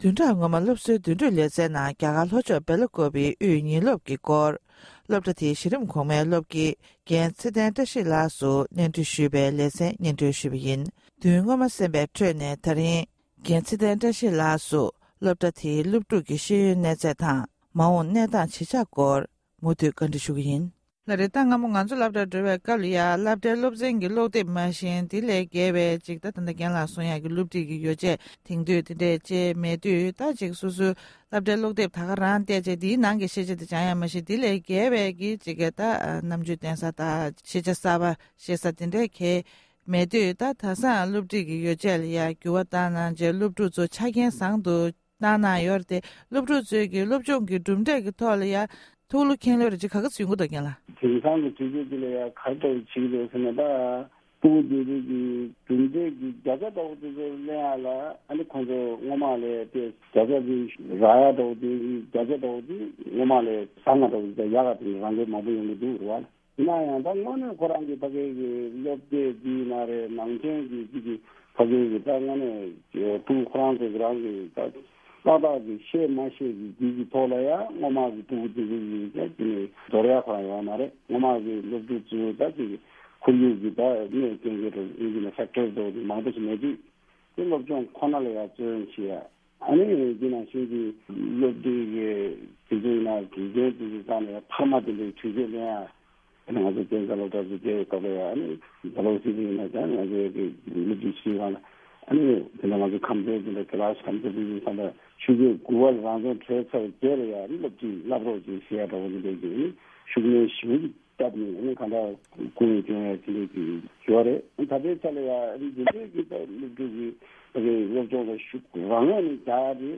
གནས་འདྲི་ཞུས་པའི་ལེ་ཚན་གཉིས་པ་དེར་གསན་རོགས༎